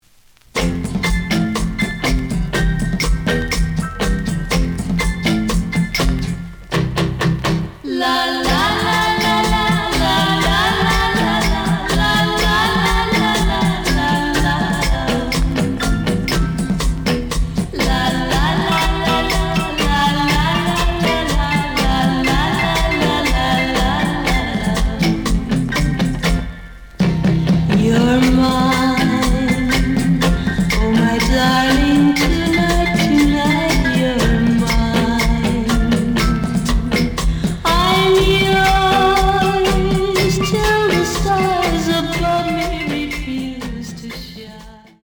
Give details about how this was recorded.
The audio sample is recorded from the actual item. Slight damage on both side labels. Plays good.)